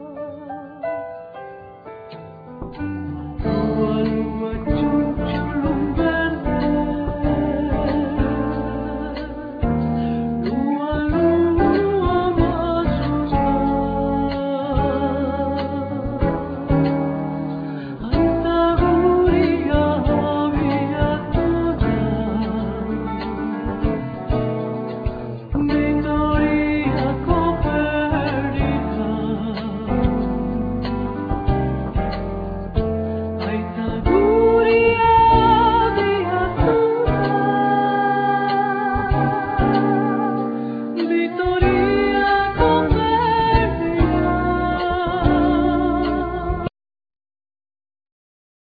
Piano,Accordion
Double bass
Drums
Percussion
Saxophone
Acoustic and electric guitar
Voice
Txalaparta